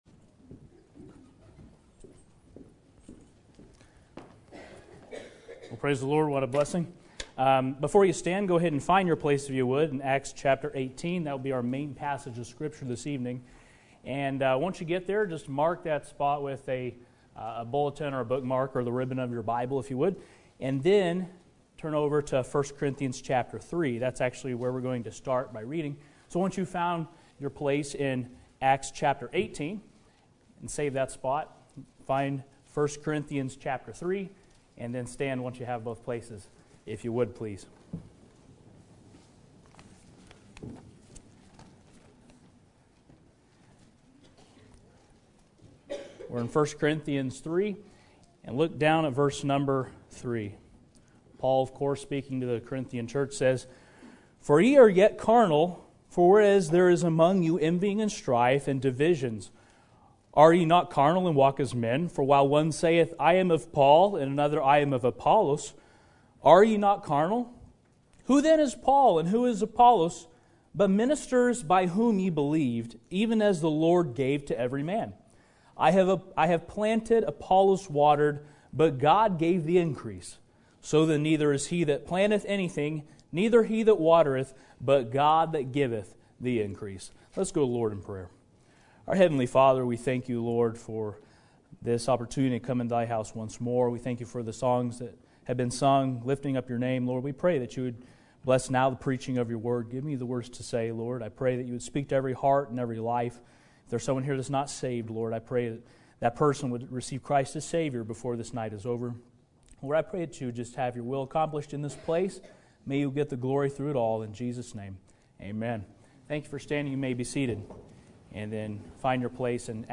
Sermon Topic: General Sermon Type: Service Sermon Audio: Sermon download: Download (13.89 MB) Sermon Tags: 1 Corinthians Planter Waterier Helper